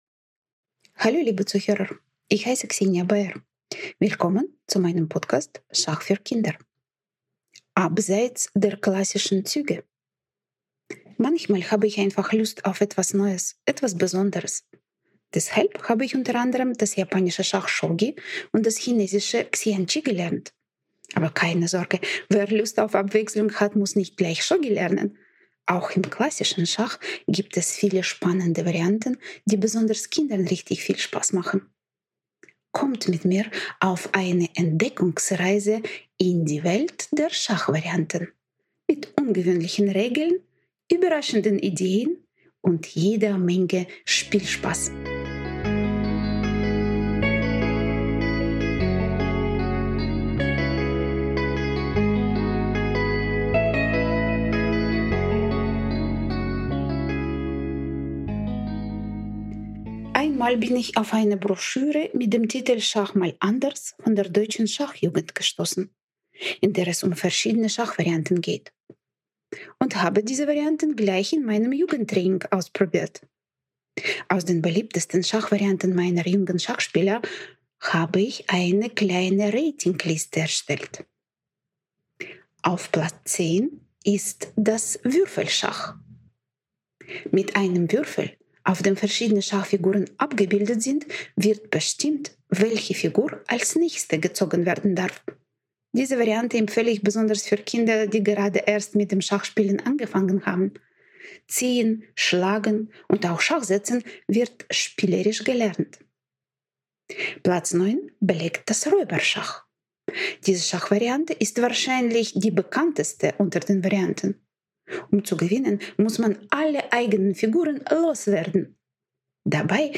Schachgeflüster ist ein Podcast rund um das Thema Schach. Er enthält Tipps zur Verbesserung sowie Interviews mit Prominenten aus der Schachwelt.